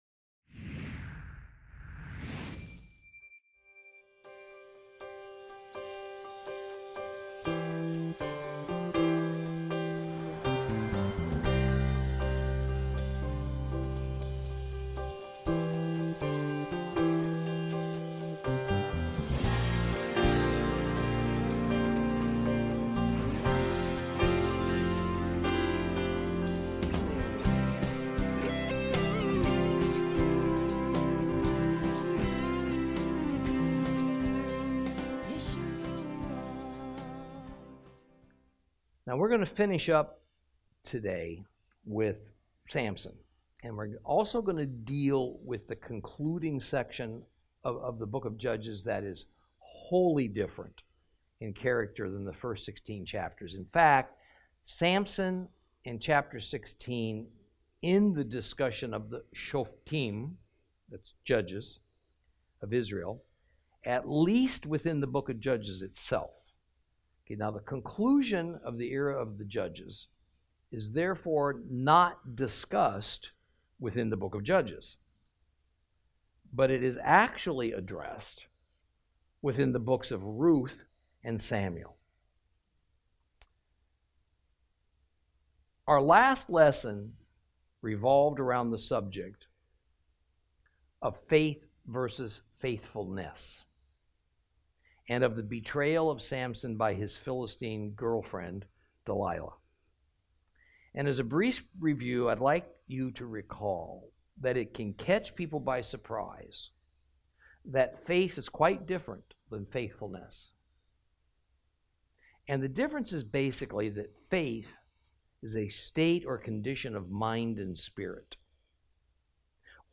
Lesson 24 Ch16 Ch17 Ch18 - Torah Class